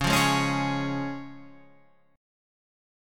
C# Major